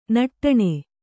♪ naṭṭaṇe